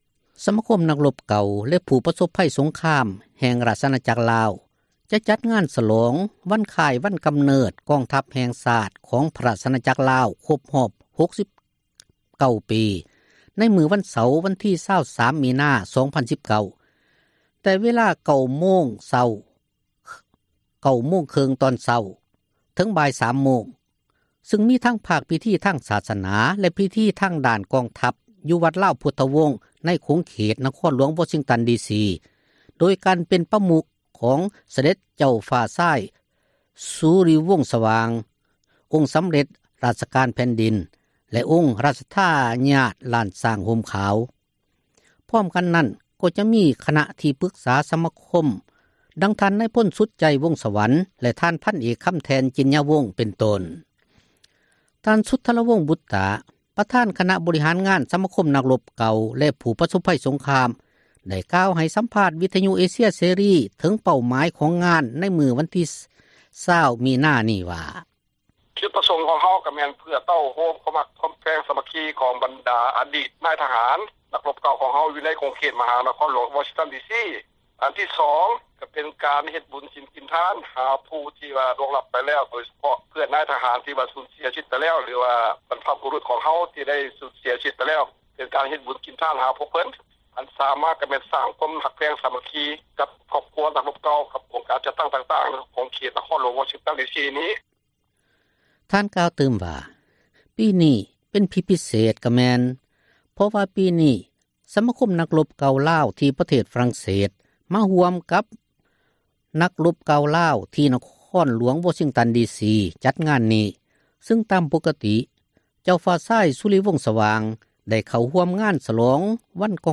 ໄດ້ກ່າວໃຫ້ສຳພາດ ວິທຍຸເອເຊັຍເສຣີ ເຖິງເປົ້າໝາຍ ຂອງງານ ໃນມື້ວັນທີ 20 ມິນາ ນີ້ວ່າ: